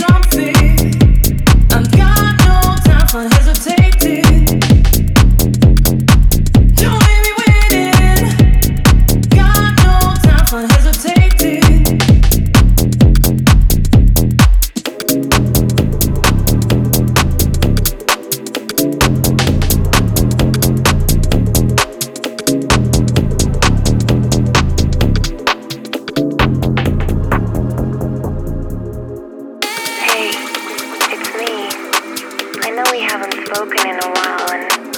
2025-10-17 Жанр: Танцевальные Длительность